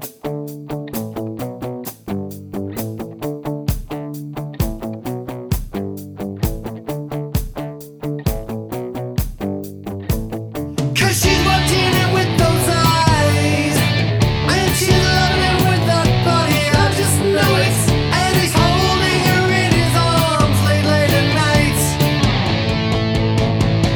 no Backing Vocals Soft Rock 3:09 Buy £1.50